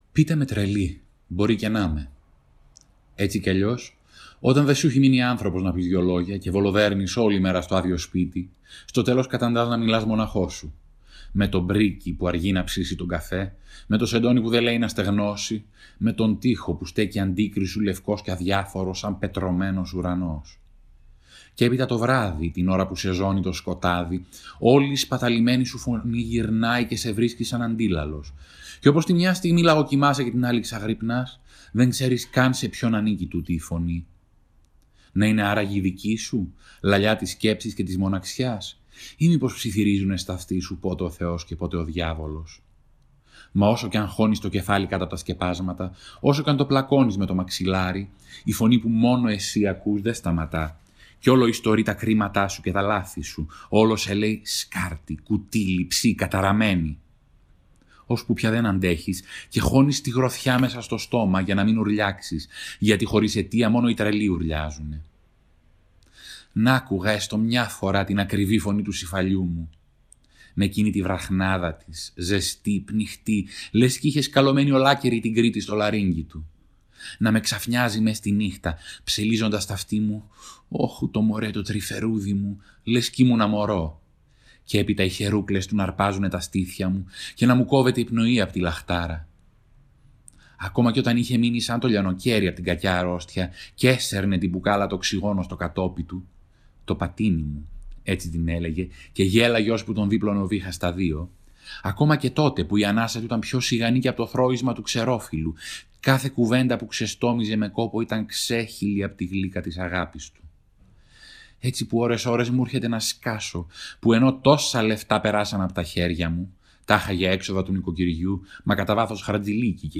Διαβάζει απόσπασμα από το βιβλίο του «Δέσποινα», εκδ. Πατάκη